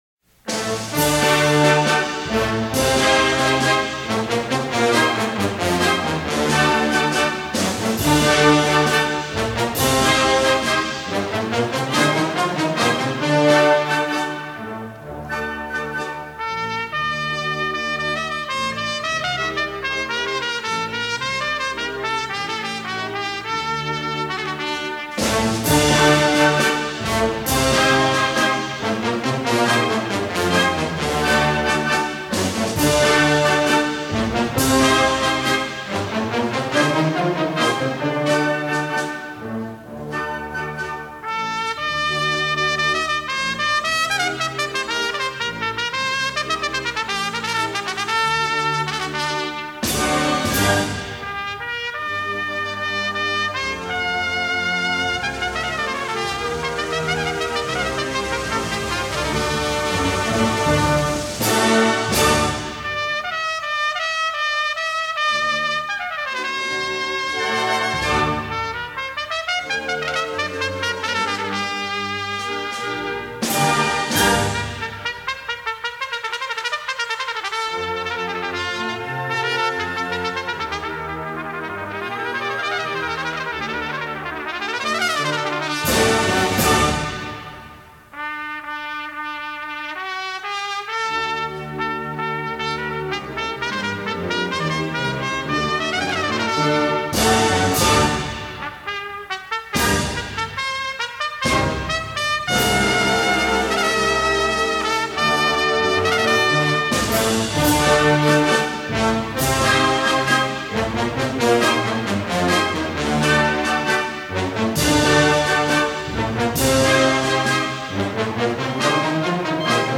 The virtuoso solo, “La Virgen de la Macarena” composed by Monterde and made famous by Rafael Mendez, was recorded live at St. Olaf College in Northfield, MN in October 1989.